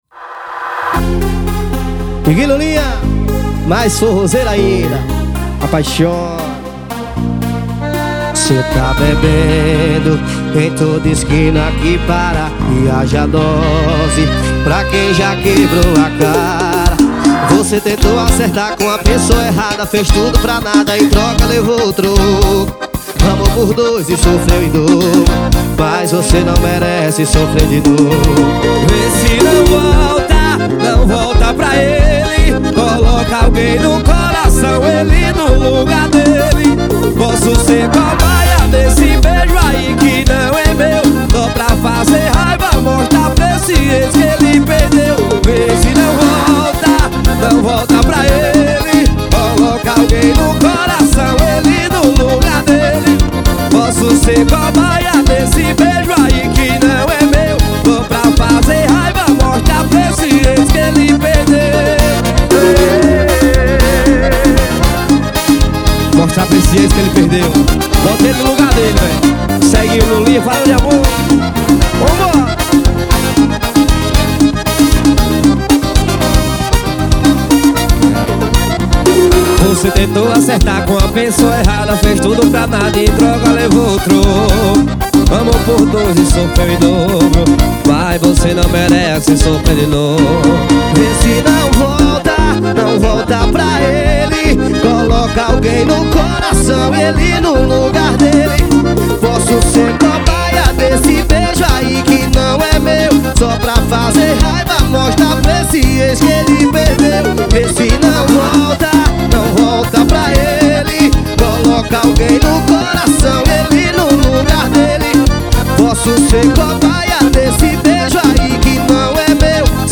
2024-02-14 18:16:49 Gênero: Forró Views